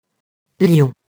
lion, lionne [ljɔ̃, ljɔn]